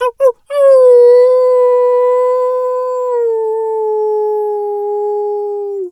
wolf_2_howl_long_02.wav